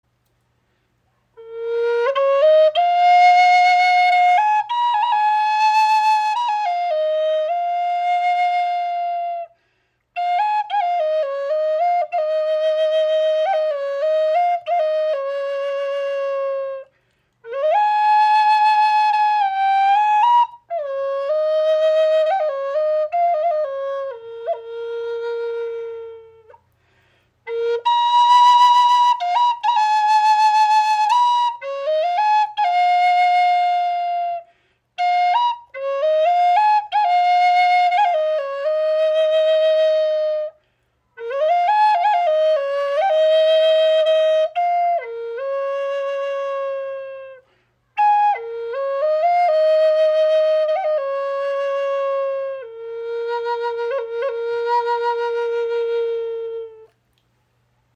Diese kleine Walnussflöte ist ideal für unterwegs und lässt ihren kräftigen Klang durch Wald und Berge schweben.